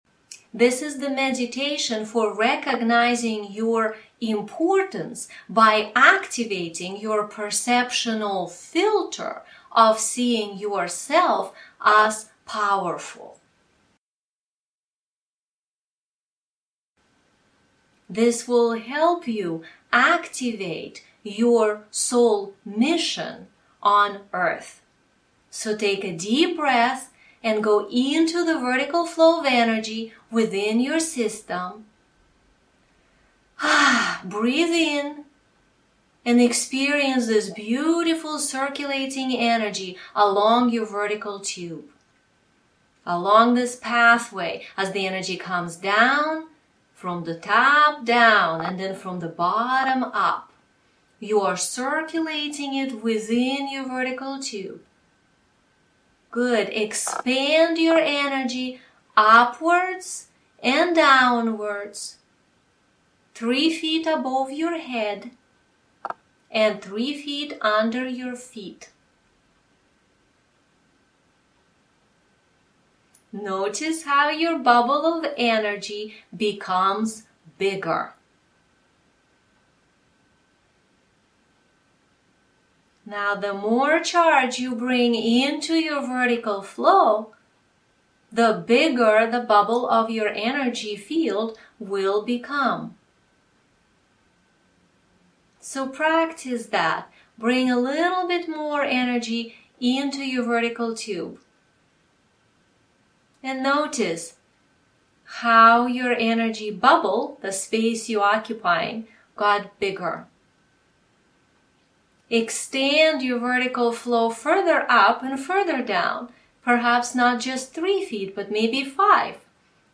recognize your Importance by using the perceptional filter of being Powerful & Confident meditation 3 Download Audio (Don’t forget to RETURN to the LESSON 3 page to CHECK OFF the box after this meditation).
Soul Lineage_Meditation3.mp3